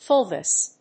/ˈfʊlvəs(英国英語)/